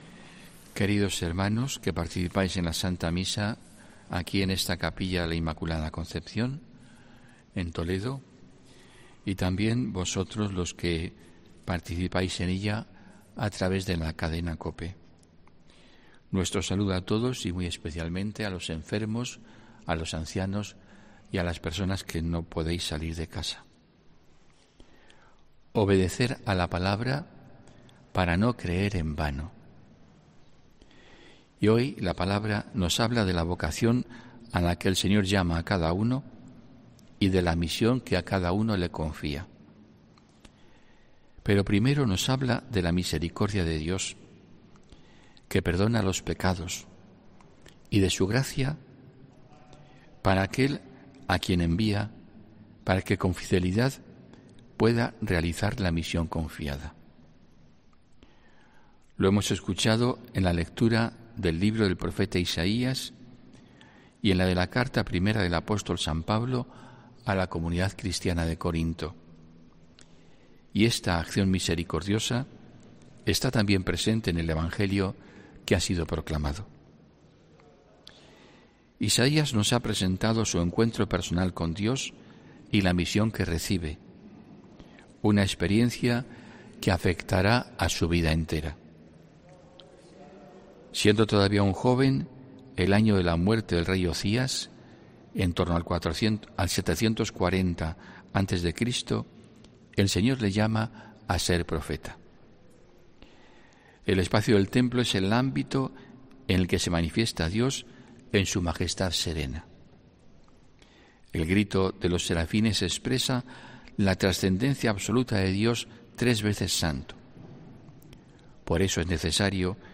HOMILÍA 6 FEBRERO 2022